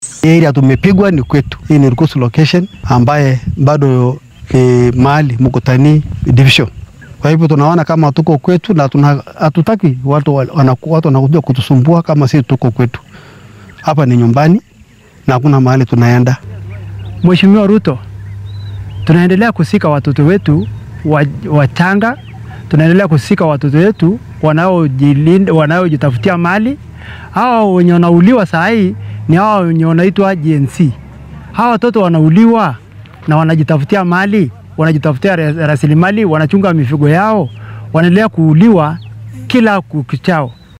Qaar ka mid ah dadweynaha Koonfurta Baringo ayaa dhacdadan uga warramay warbaahinta.
Shacabka-Baringo.mp3